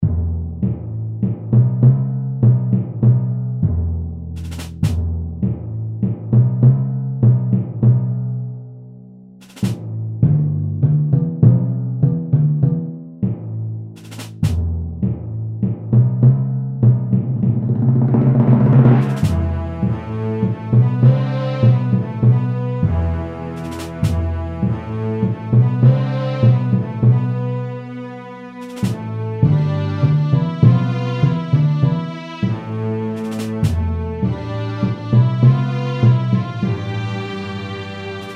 extrait version orchestrée par mon filleul
chansonnetteorchestree.mp3